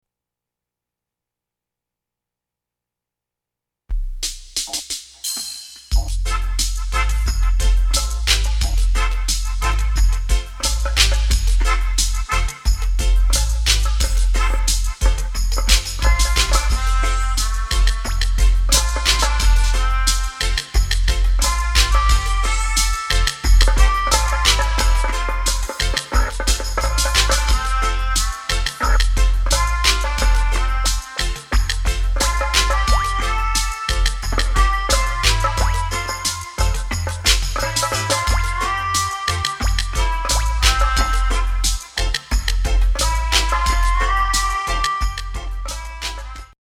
MEDITATIVE MELODICA & VOCALS